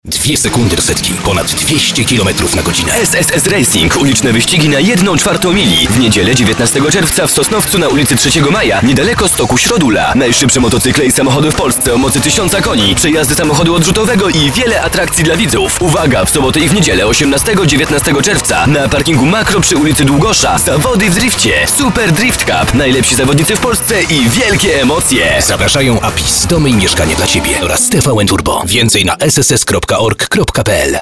drift spot 2011.mp3